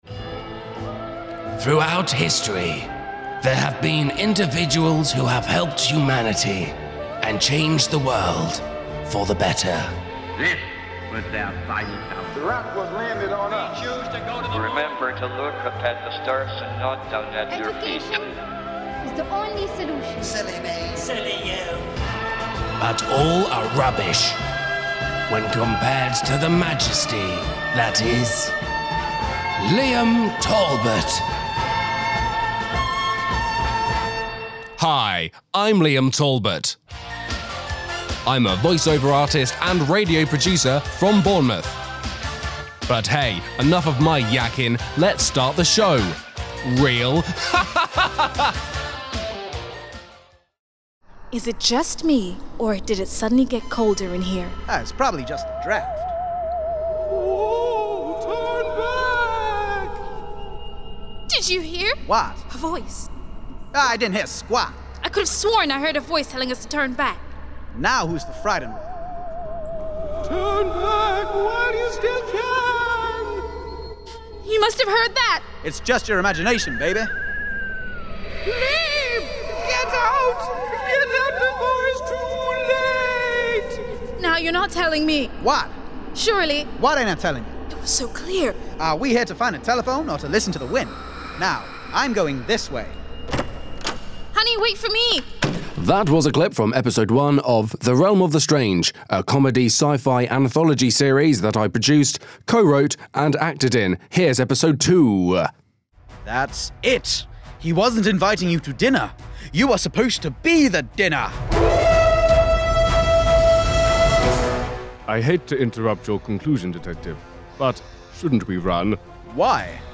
This showreel demonstrates my skills in voice over, audio editing and production. It is a compilation of pieces I have made during my three years at university as well as a clip I acted in.